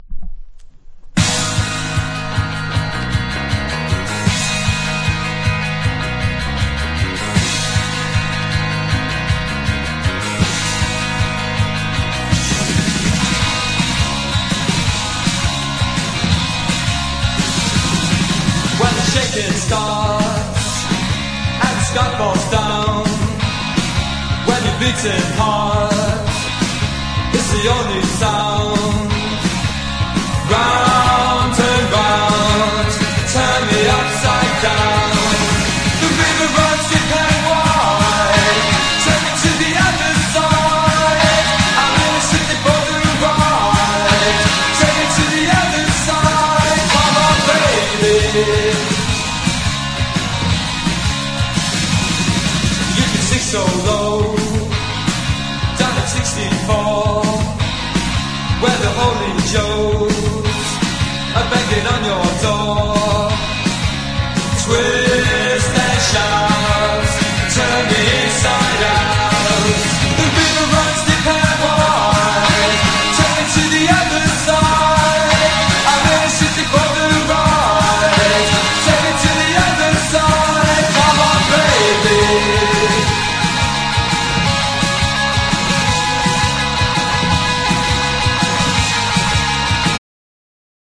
# NEO ACOUSTIC / GUITAR POP